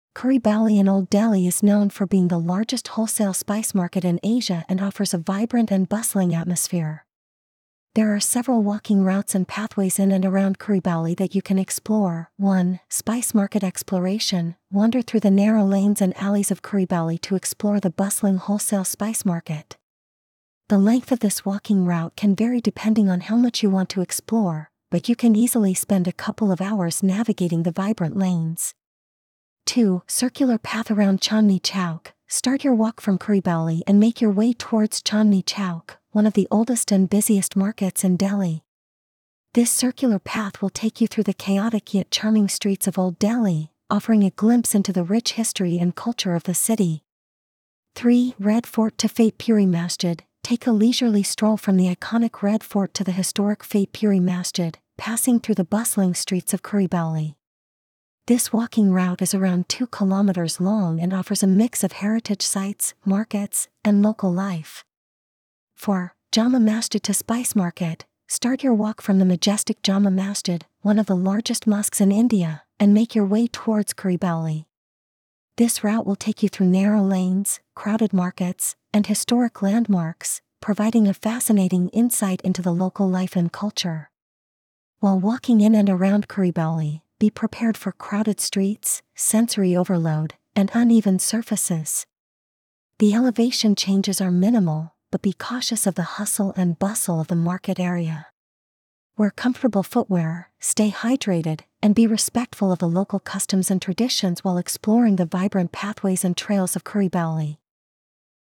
Visit Khari Baoli in New Delhi, Asia's largest spice market. Discover local secrets with AccessTravel's free audio guides.